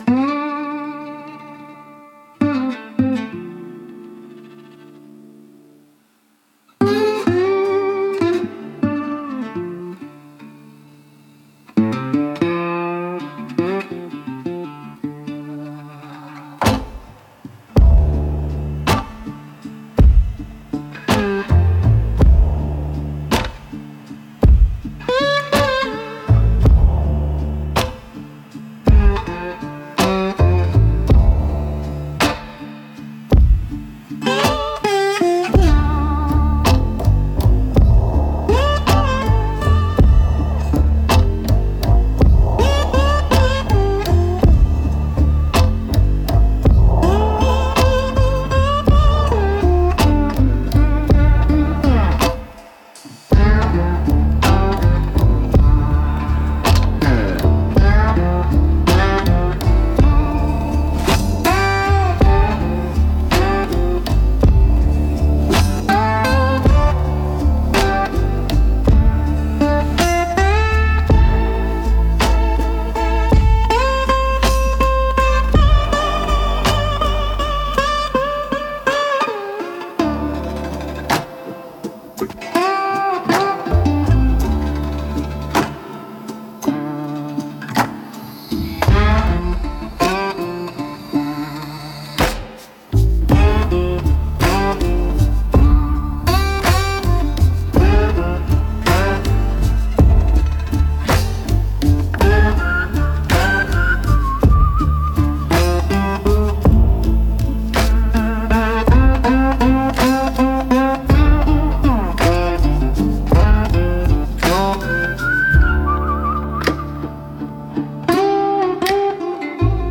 Instrumental - The Last Grit in the Gulch 2.04